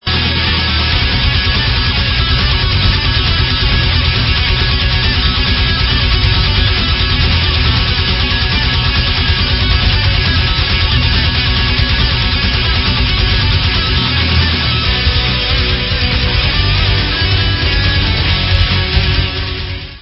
CZECH FEMALE FRONTED POWER METAL BAND